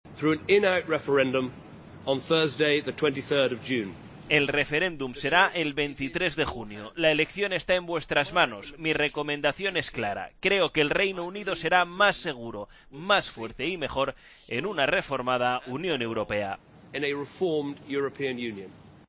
Cameron, Primer Ministro Británico: "El referéndum será el 23 de junio, creo que el Reino Unido será mejor en una reformada Unión Europea"